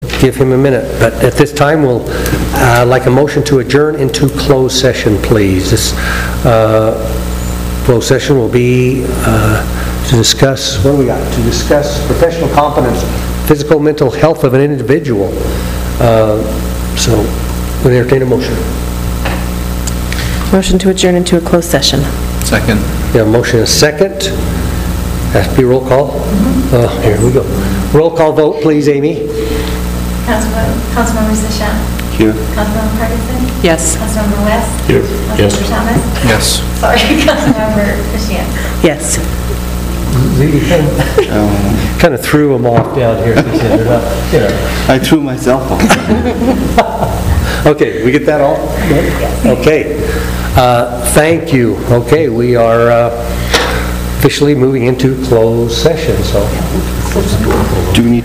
City Council Meeting-AMENDED